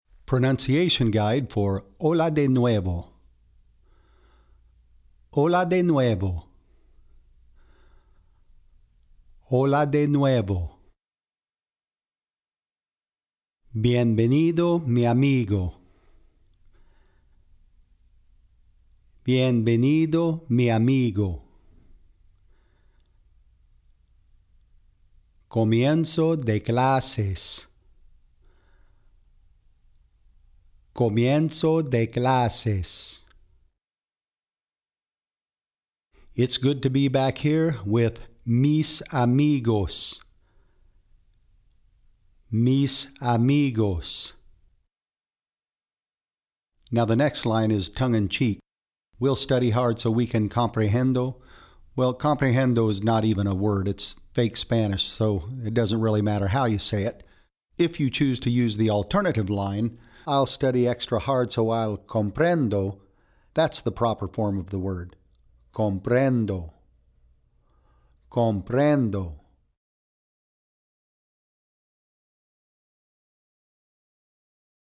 Hola De Nuevo - Pronunciation Guide
Here's a pronunciation guide for the Spanish words in this song from Music K-8, Vol. 27, No. 1.
x271HolaDeNuevoPronunciation.mp3